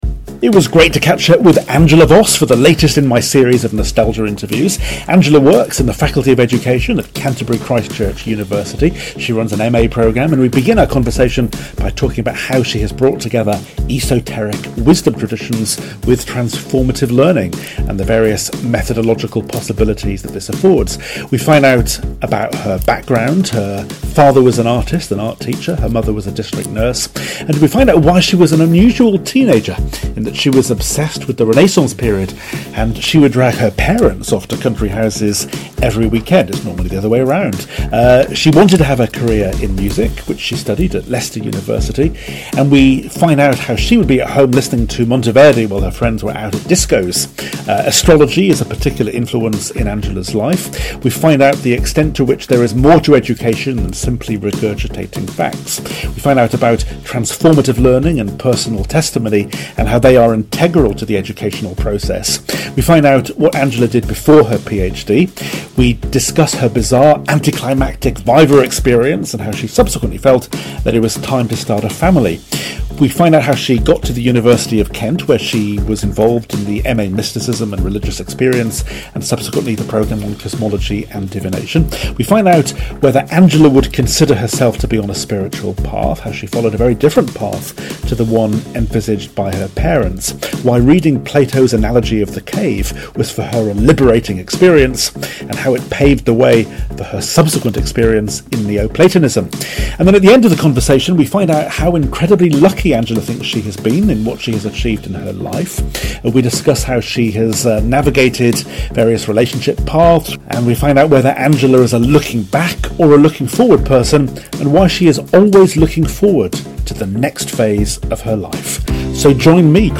Nostalgia Interviews